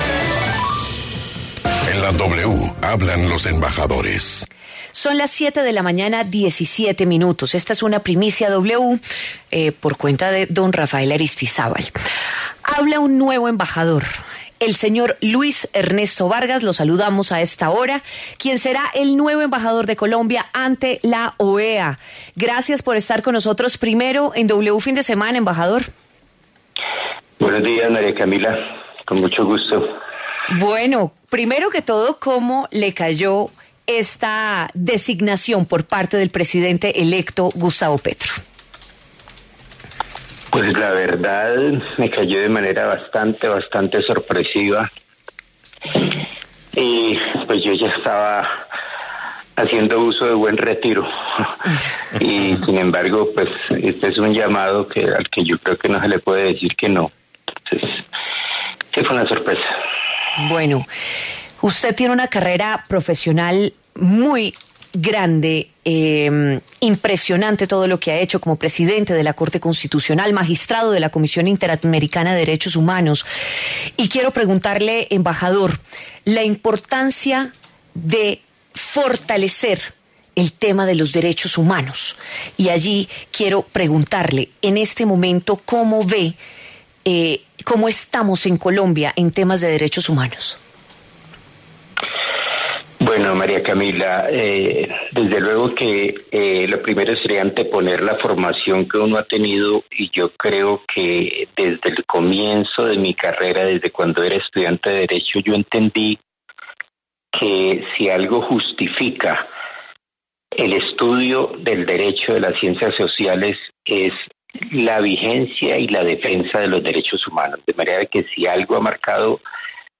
En diálogo con W Fin de Semana, el embajador designado Luis Ernesto Vargas fue enfático en señalar que, bajo su representación, el discurso de Colombia sobre el aborto cambiará radicalmente, criticando la prohibición que defiende Alejandro Ordóñez.